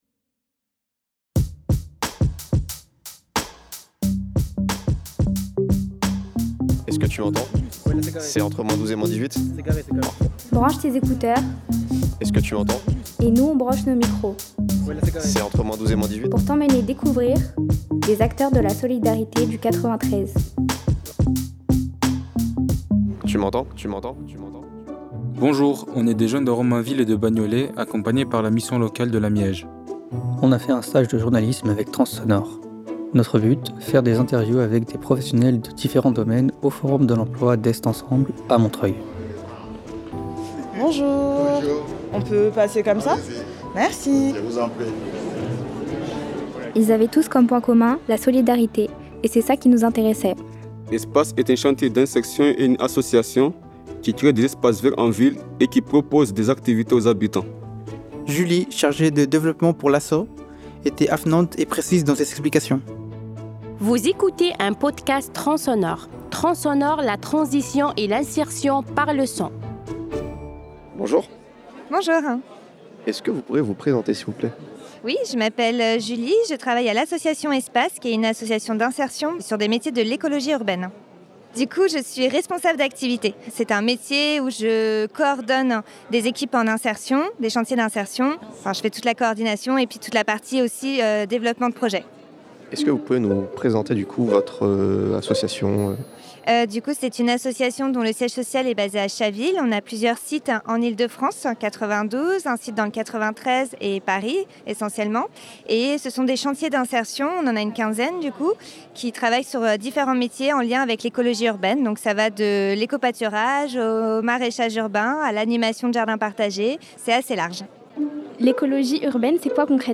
On va vous faire écouter les interviews qu’on a menées aux côtés de transonore en tant qu’apprenti·es journalistes, alors mettez vos écouteurs ou vos casques, et rejoignez notre aventure au cœur du forum de l’emploi d’Est Ensemble, à Montreuil.